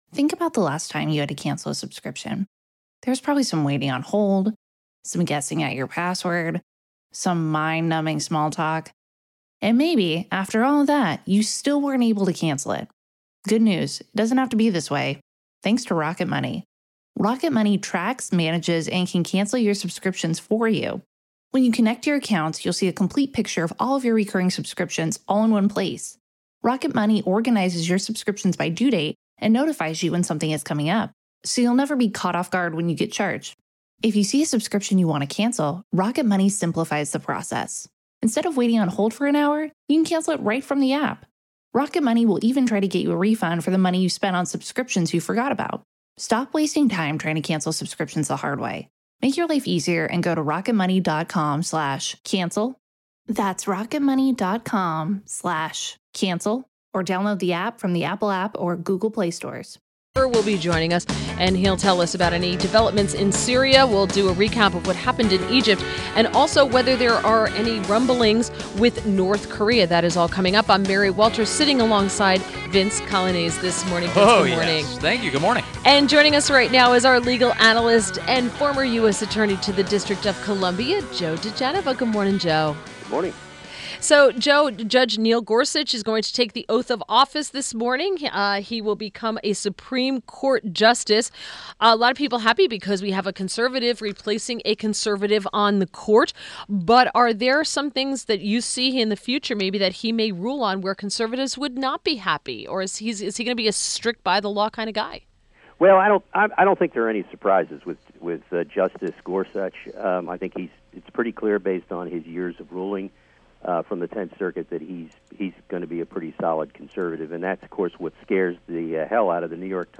INTERVIEW - JOE DIGENOVA - legal analyst and former U.S. Attorney to the District of Columbia